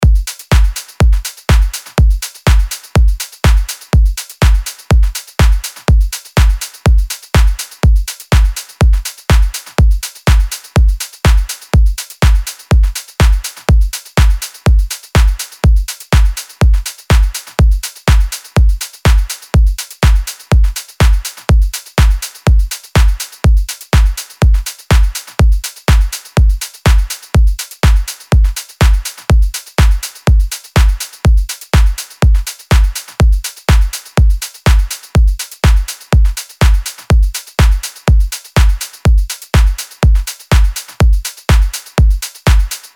LP 207 – DRUM LOOP – EDM – 123BPM